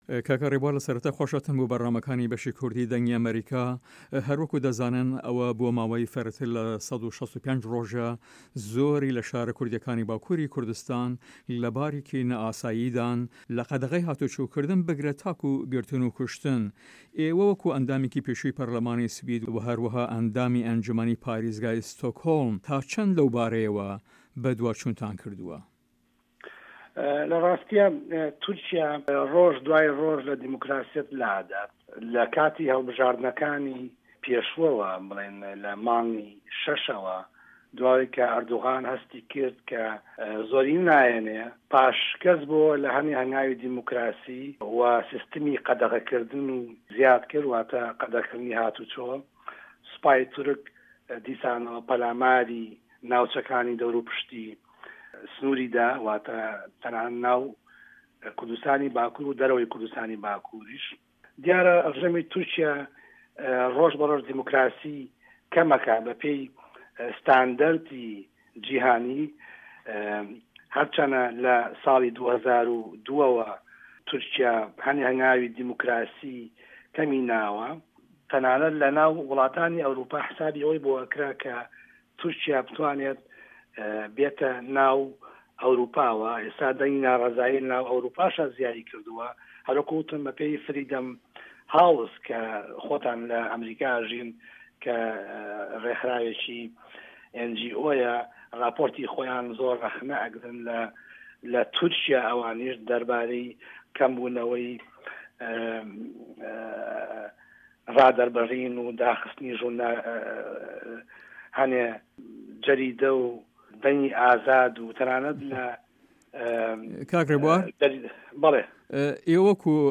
رێبوار حەسەن ئەندام پەرلەمانی پێشووێ سوید و ئەندامی ئەنجومەنی ئوستان وە یاخود پاریزگای ستۆکهۆڵم لە هەڤپەیڤینێکدا لەگەڵ بەشی کوردی دەنگی ئەمەریکا دەڵێت" ئێمە لای خۆمانەوە هەوڵی ئەوەمان داوە را درووست بکەین لە ناو کەمەڵگای سویدی دا، نەک هەر من بە تەنها بەڵکو چالاکوانانێکی زۆری کورد لە سوید کە رۆژانە لە پەیوەندیدان لەگەڵ یەکتردا بۆ ئەوەی را درووست بکەین لە دژی ئەو پێشیلیانەی کە تورکیا دەیکات بەرامبەر بە مافەکانی مرۆڤ و گەلی کورد، چ لە ناوەوەی تورکیا وە یاخود لە دەرەوە وەکو ئەو لەشکەر کشیەی لە باشوری کوردستان.